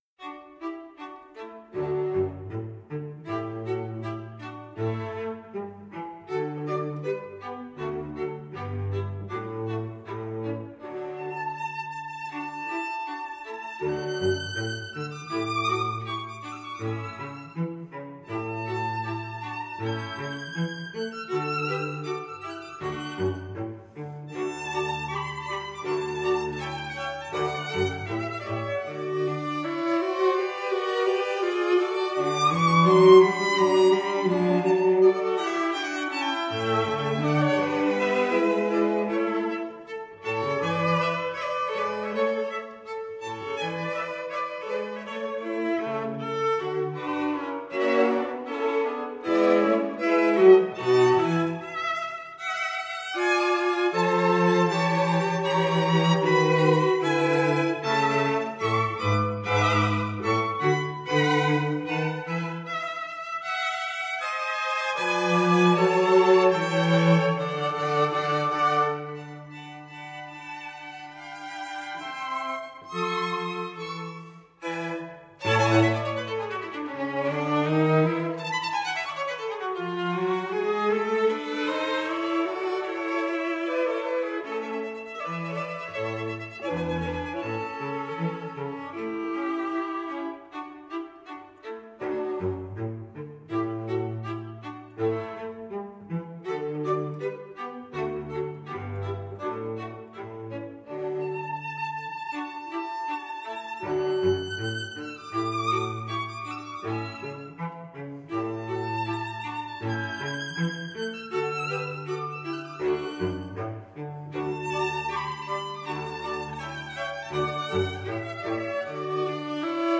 Author Musopen String Quartet